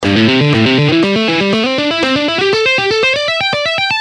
This hybrid picking style combines the best of alternate picking and sweep picking, enabling you to play scales, arpeggios, and complex runs with smoothness and precision.
economy-picking-lesson.gpx-3.mp3